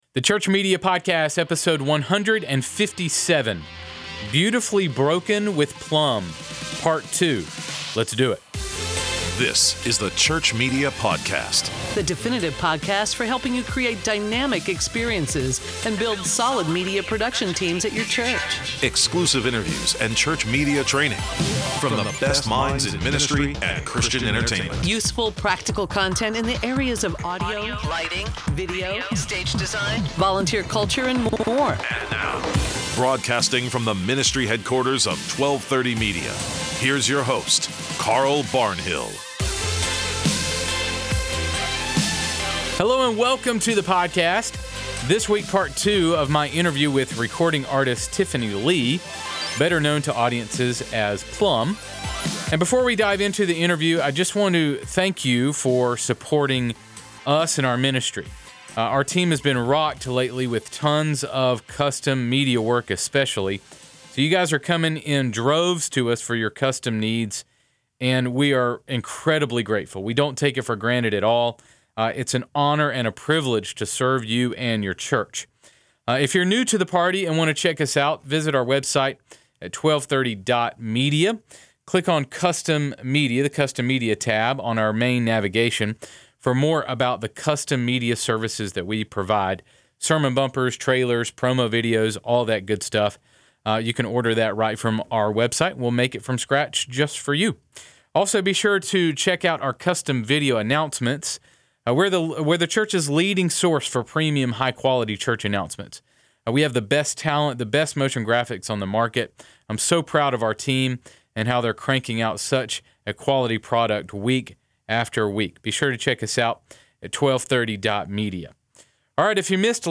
We continue our conversation with recording artist Plumb.